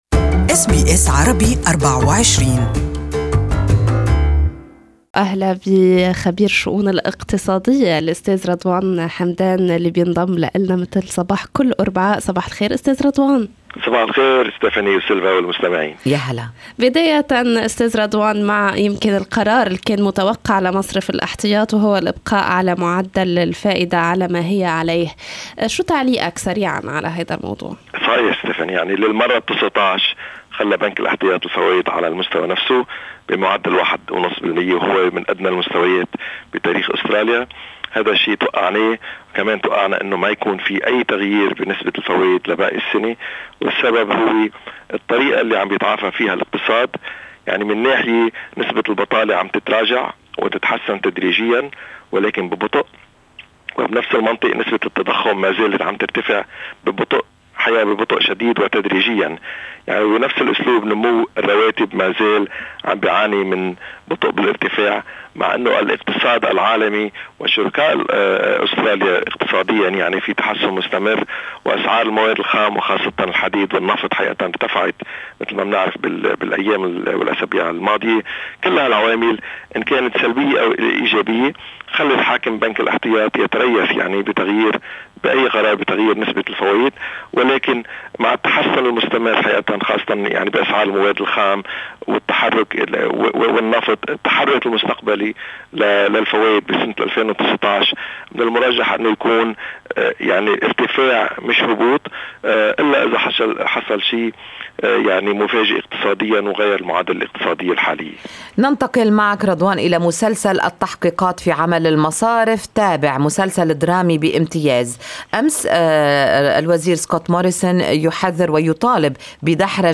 للإستماع إلى اللقاء الكامل يمكنكم الضغط على التدوين الصوتي أعلاه استمعوا هنا الى البث المباشر لاذاعتنا و لاذاعة BBC أيضا حمّل تطبيق أس بي أس الجديد على الأندرويد والآيفون للإستماع لبرامجكم المفضلة باللغة العربية.